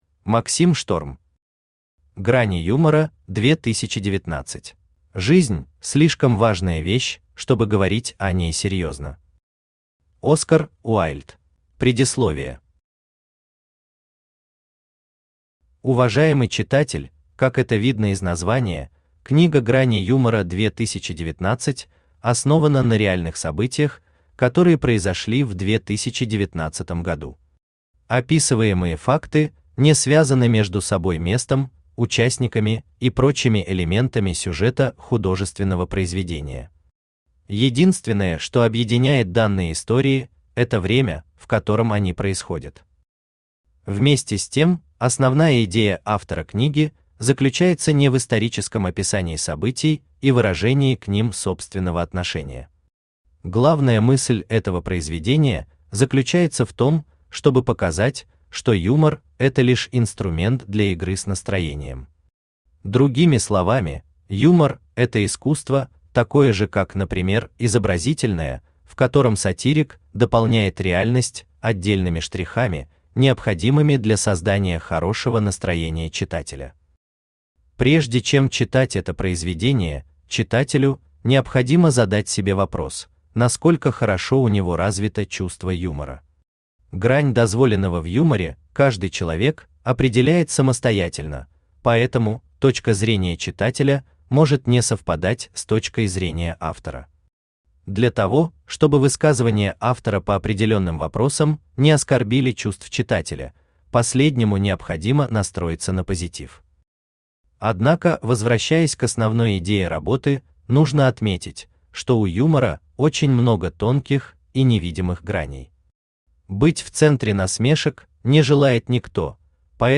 Аудиокнига Грани юмора 2019 | Библиотека аудиокниг
Aудиокнига Грани юмора 2019 Автор Максим Шторм Читает аудиокнигу Авточтец ЛитРес.